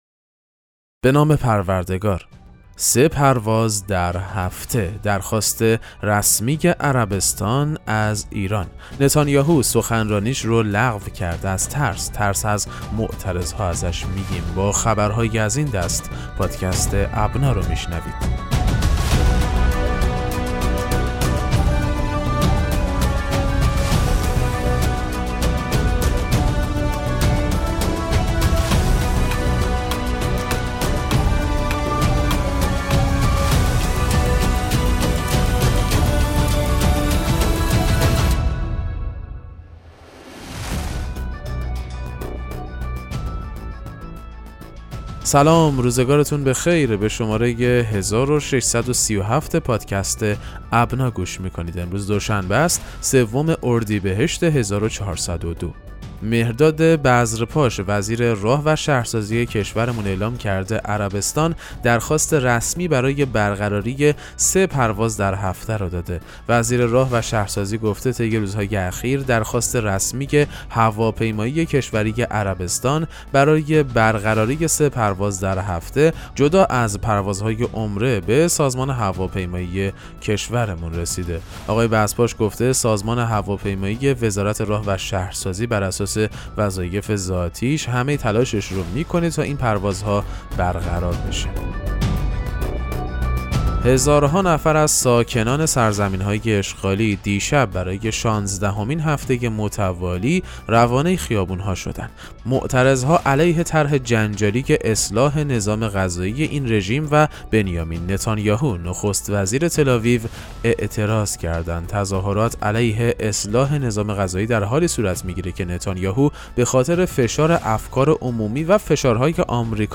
پادکست مهم‌ترین اخبار ابنا فارسی ــ سوم اردیبهشت 1402